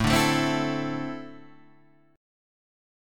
A4-3 chord